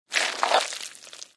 Здесь собраны разнообразные аудиоэффекты: от мягкого постукивания по кожуре до сочного хруста при разрезании.
Звук чистки тыквы: достаем семечки